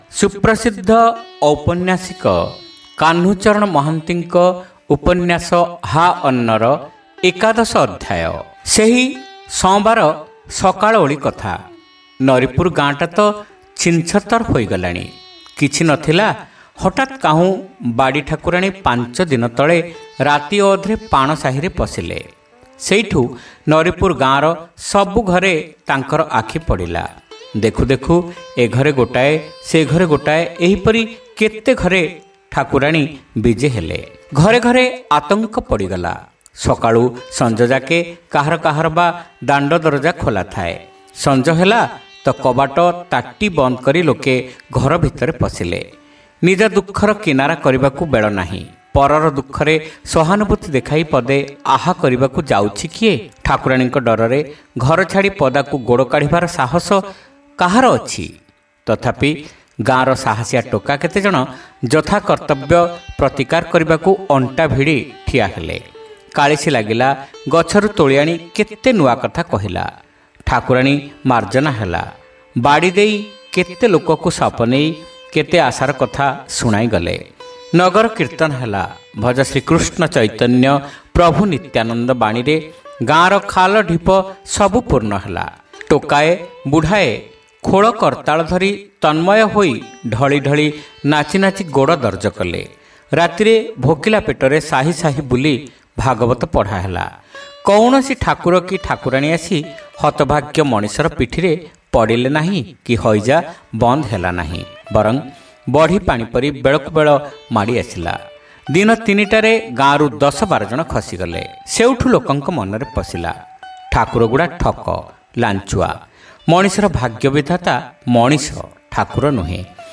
ଶ୍ରାବ୍ୟ ଉପନ୍ୟାସ : ହା ଅନ୍ନ (ଏକାଦଶ ଭାଗ)